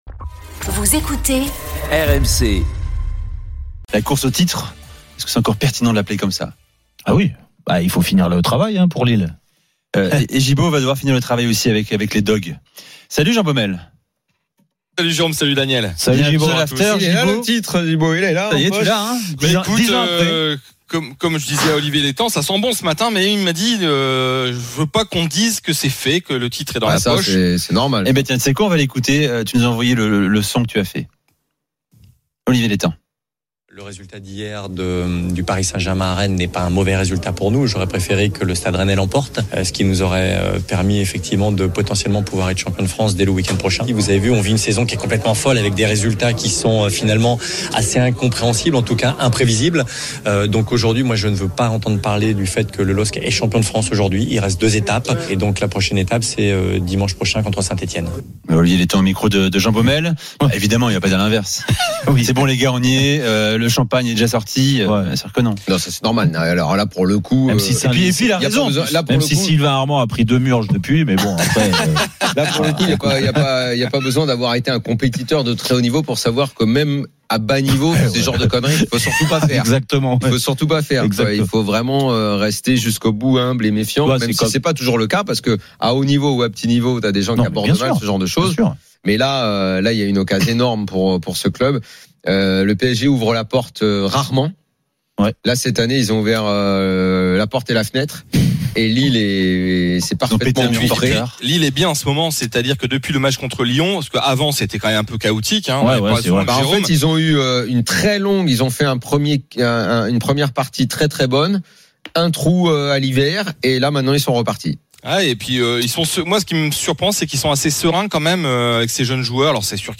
Chaque jour, écoutez le Best-of de l'Afterfoot, sur RMC la radio du Sport !
avec les réactions des joueurs et entraîneurs, les conférences de presse d'après-match et les débats animés entre supporters, experts de l'After et auditeurs